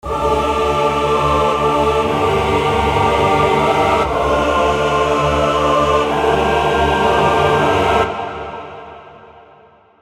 Chor VSTi mit dem Sound der 90iger gesucht.
Der Chor kommt aus dem Korg NS5R, für meinen persönlichen Geschmack immer noch der beste Chor für Metal Produktionen.